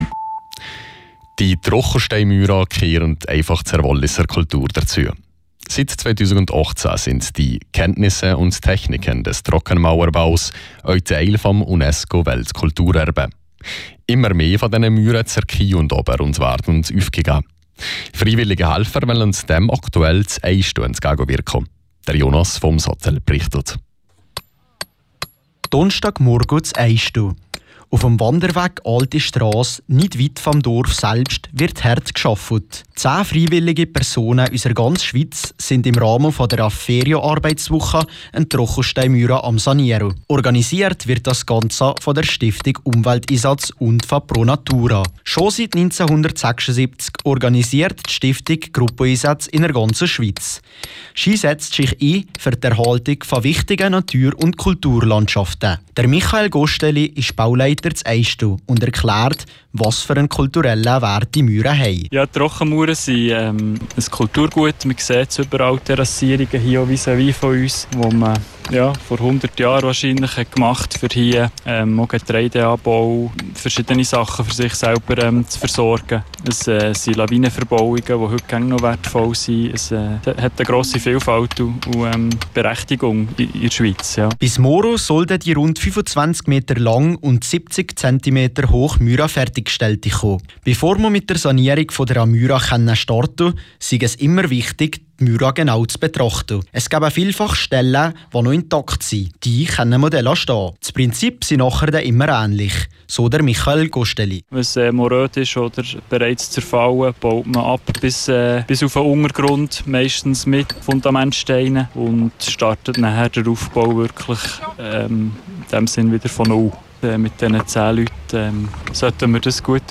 RRO-Beitrag zum Projekt in Eisten